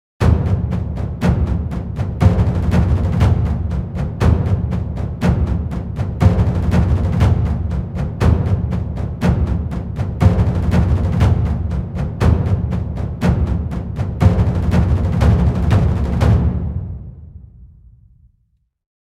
Dramatic Tension Tympany Beat Sound Effect
Description: Dramatic tension tympany beat sound effect. Build tension with deep, powerful drum hits in the tension dramatic tympany beat sound effect.
Genres: Sound Logo
Dramatic-tension-tympany-beat-sound-effect.mp3